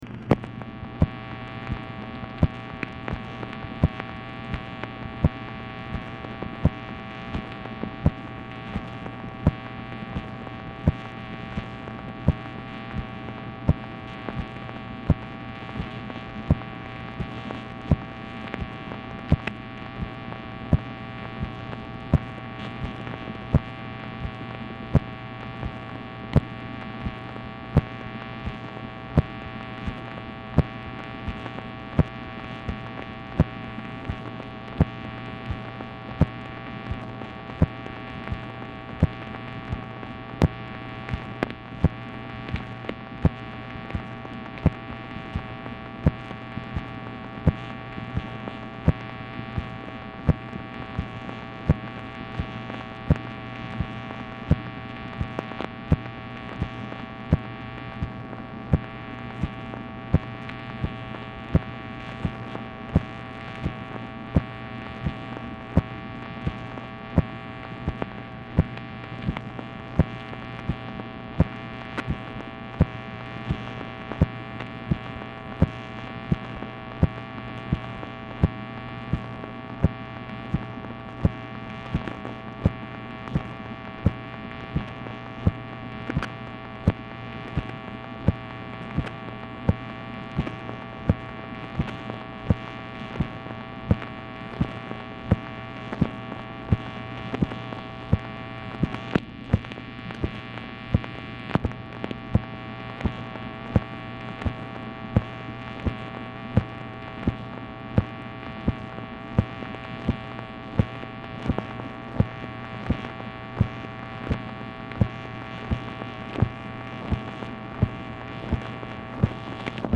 Telephone conversation # 12820, sound recording, MACHINE NOISE, 3/17/1968, time unknown | Discover LBJ
Format Dictation belt
Location Of Speaker 1 LBJ Ranch, near Stonewall, Texas
White House Telephone Recordings and Transcripts Speaker 2 MACHINE NOISE